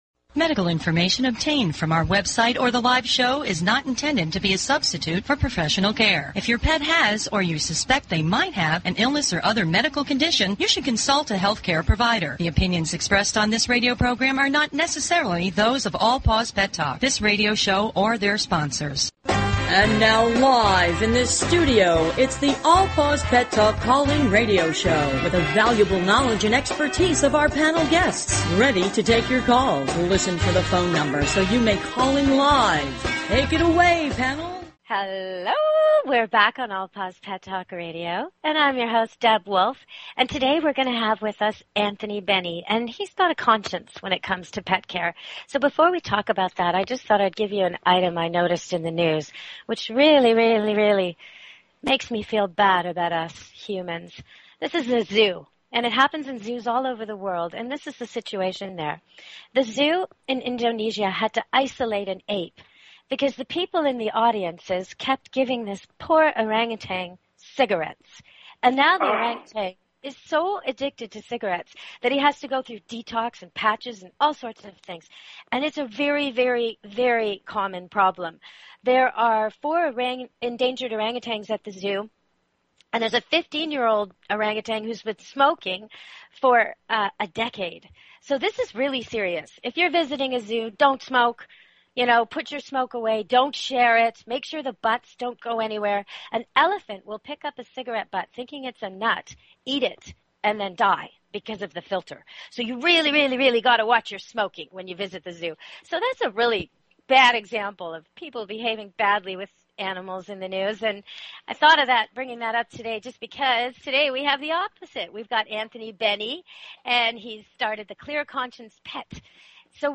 Talk Show Episode, Audio Podcast, All_Paws_Pet_Talk and Courtesy of BBS Radio on , show guests , about , categorized as
All Paws Pet Talk is directed to the millions of owners who are devoted to their pets and animals. Our hosts are animal industry professionals covering various specialty topics and giving free pet behavior and medical advice. We give listeners the opportunity to speak with animal experts one on one.